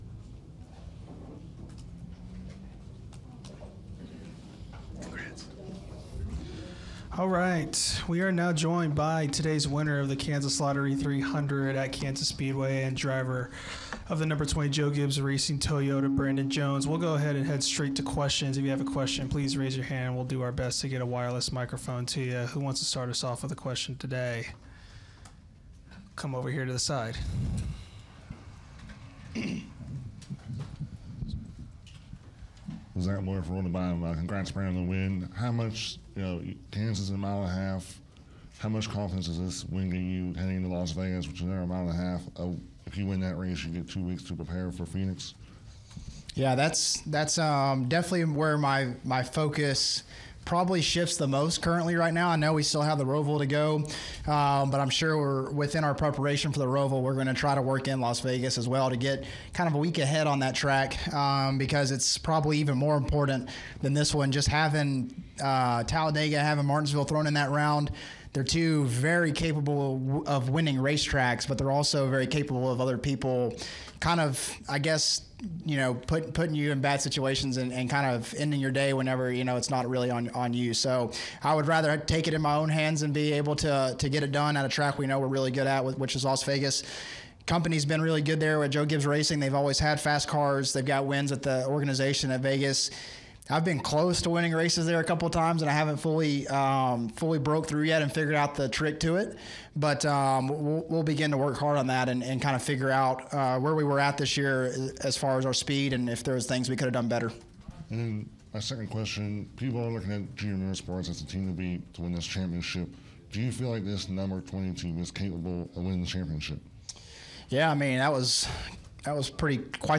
Interview: NXS Race Winner Brandon Jones (No. 20 Joe Gibbs Racing Toyota) –